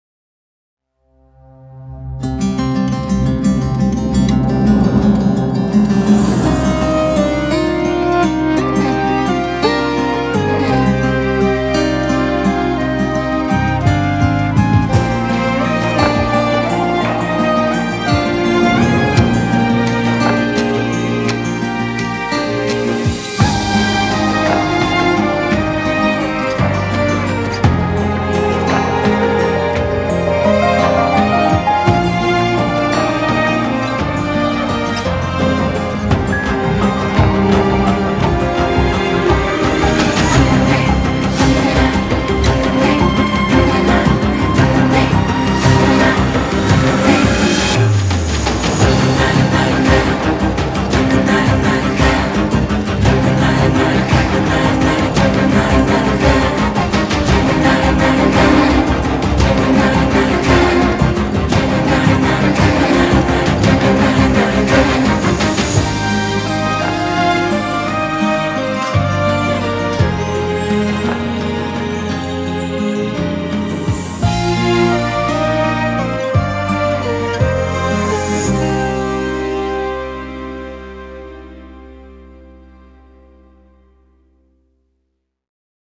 tema dizi müziği, duygusal hüzünlü heyecan fon müziği.